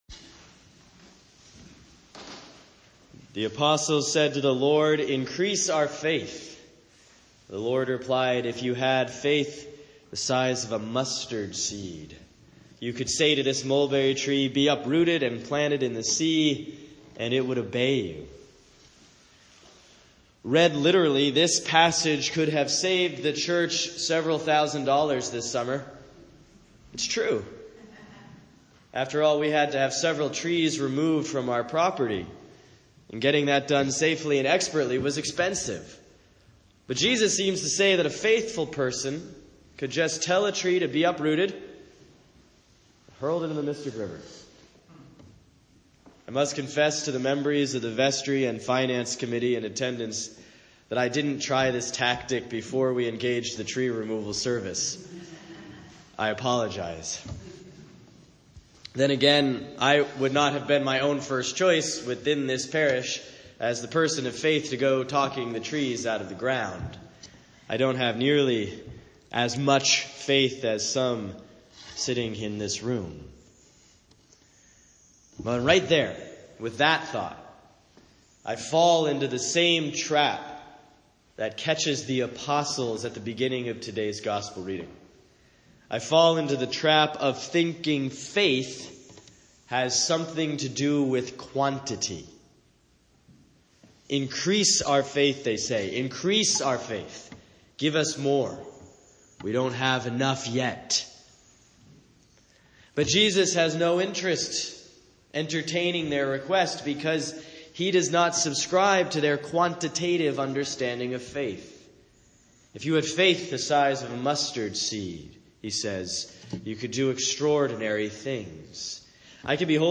Sermon for Sunday, May 19, 2013 || Pentecost, Year C || John 14:8-17, 25-27